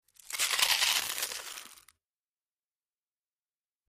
BiteManyPotatoChip PE390704
Bite Into Many Potato Chips, X7